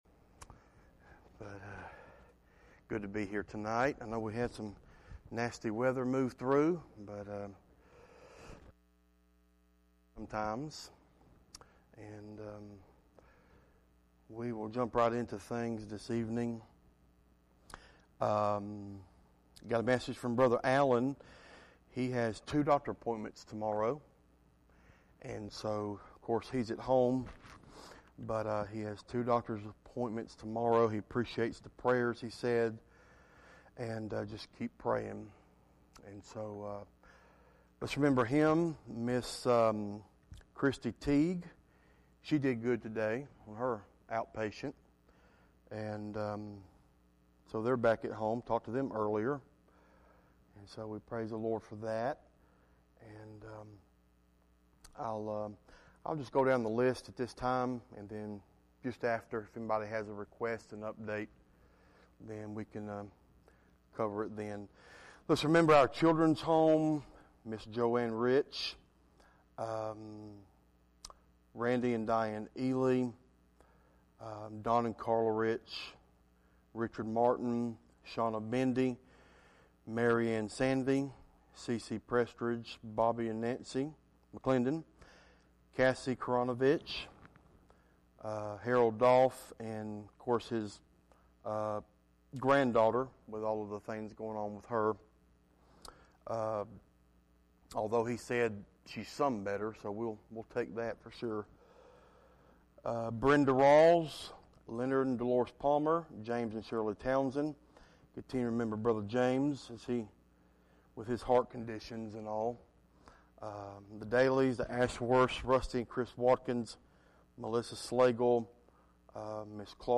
Judges 13:24 Service Type: Midweek Meeting « Filthy Dreamers